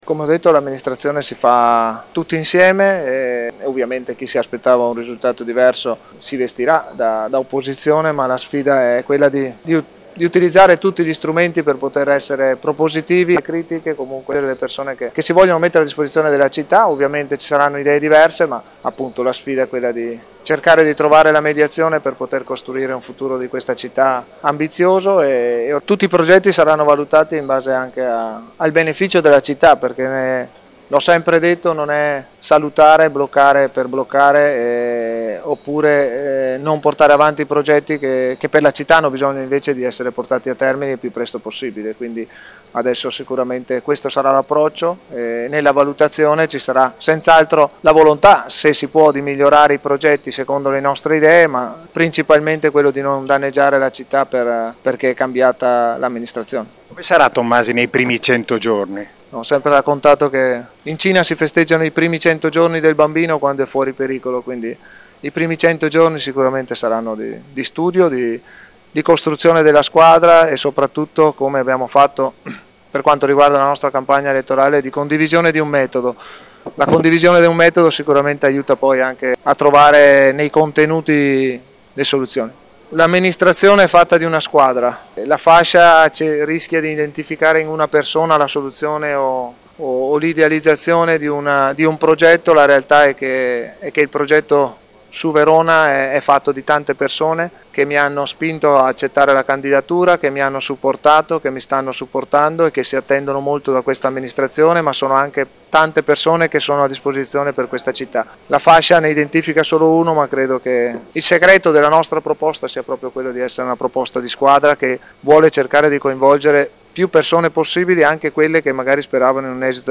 Si è svolta nella giornata di mercoledì 29 giugno, presso Palazzo Barbieri, la cerimonia di proclamazione dei risultati ufficiali delle elezioni che hanno decretato Damiano Tommasi nuovo Sindaco di Verona. Concretezza, responsabilità, gioco di squadra, sono questi i punti da cui parte l’agenda del neo sindaco. Tommasi si è detto soddisfatto della politica scelta e speranzoso che essa diventi buona amministrazione, permettendo a Verona di ambire a ciò che merita.
Le sue parole nell’intervista realizzata dal nostro corrispondente